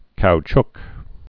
(kouchk, -chk)